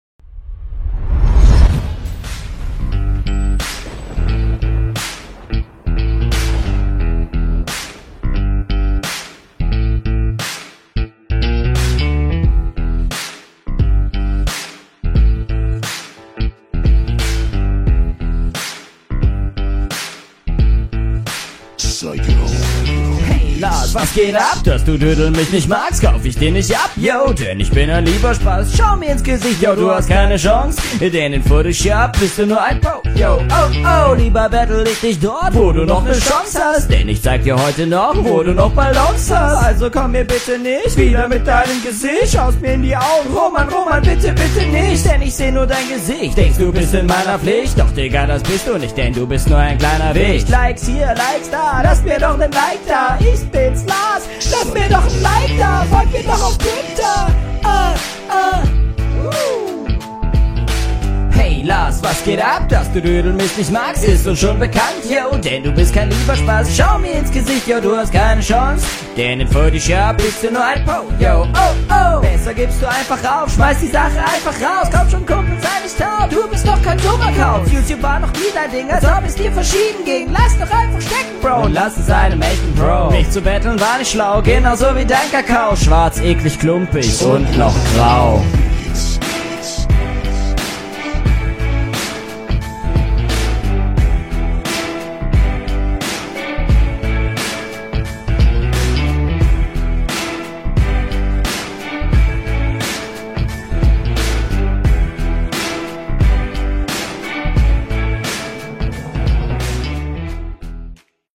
Der Stimmeinsatz ist für mich komplett drüber. Der Takt wird getroffen, aber mehr auch nicht.